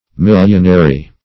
millionary.mp3